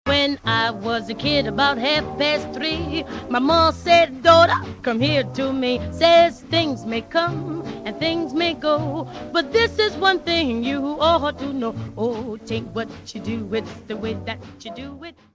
Original Restored Recordings Deutsch Français